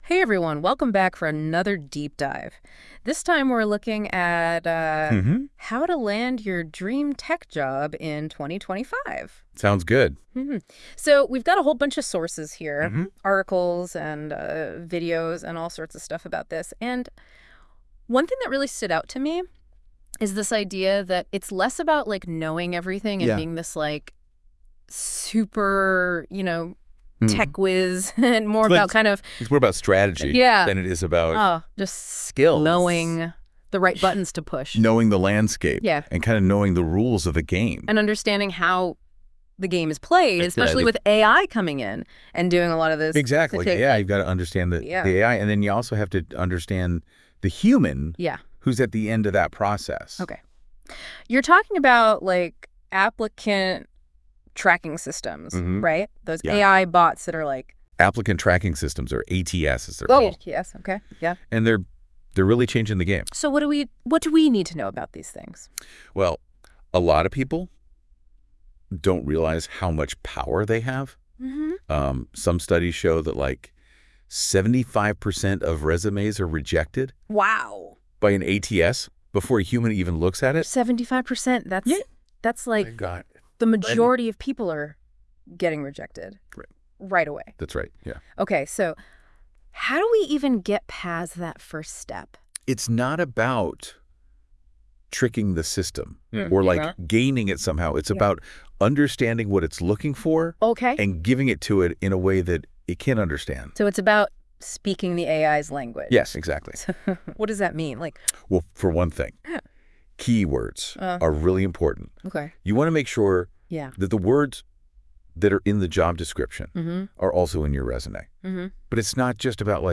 In this Deep DIVE: Your friendly AI hosts talk about landing your dream tech job in 2025?